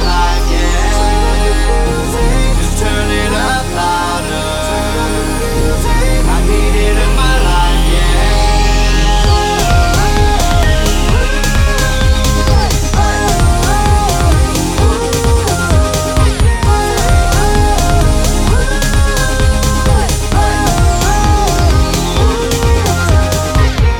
no Backing Vocals Dance 3:47 Buy £1.50